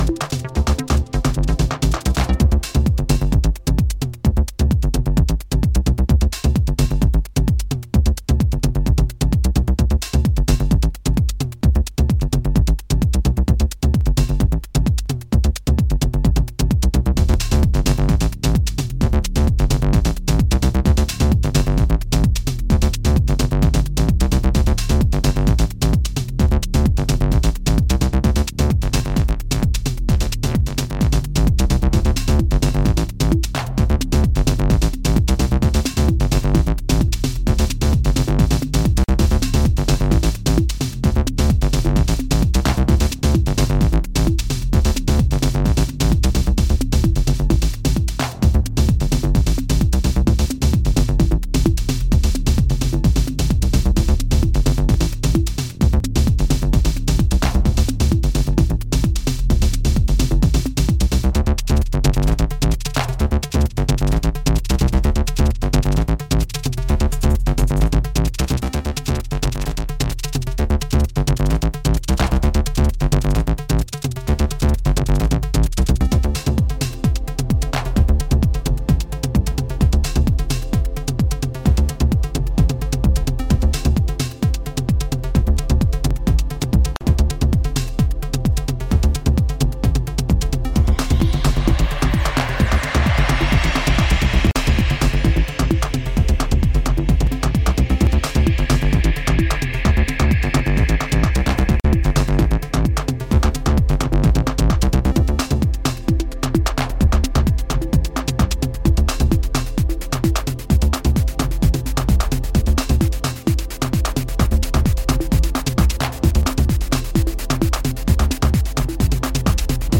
アシッド音を絡め11分に及ぶヒプノティック・グルーヴを展開する
Used Acid House